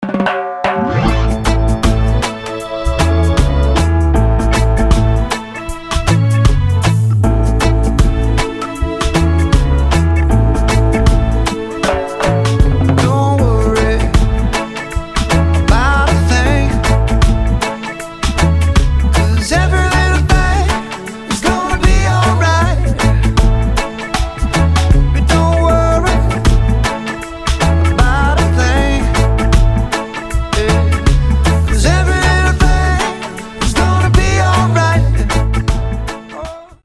• Качество: 320, Stereo
позитивные
спокойные
регги
расслабляющие
кавер
Reggae